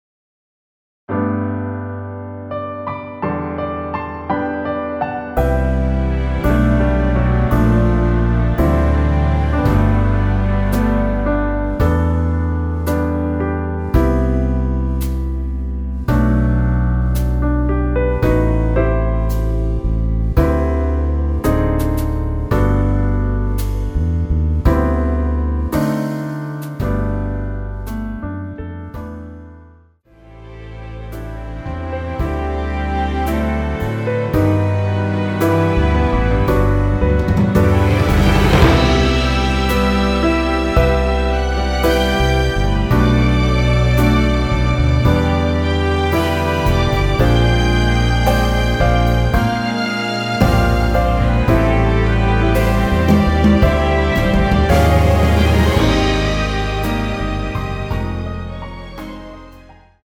원키에서(-1)내린 MR입니다.
◈ 곡명 옆 (-1)은 반음 내림, (+1)은 반음 올림 입니다.
앞부분30초, 뒷부분30초씩 편집해서 올려 드리고 있습니다.